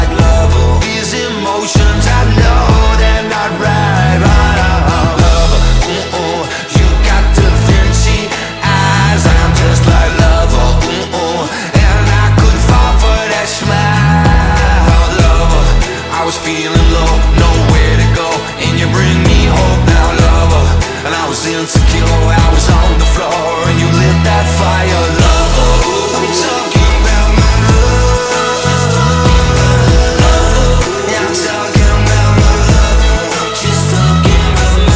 2025-05-23 Жанр: Альтернатива Длительность